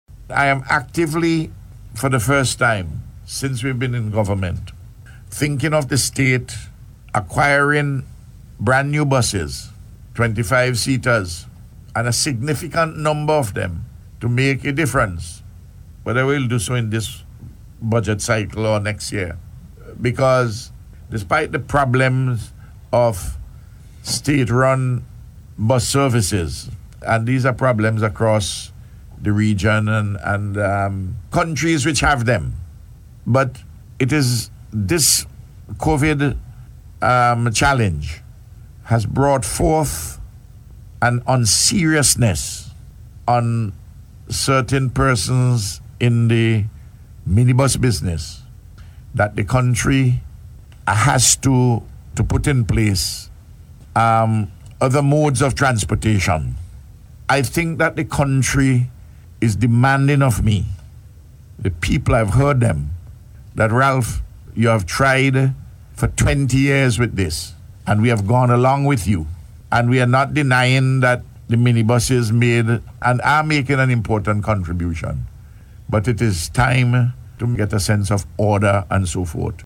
This was among matters addressed by Prime Minister Dr. Ralph Gonsalves, while speaking on Radio on Monday.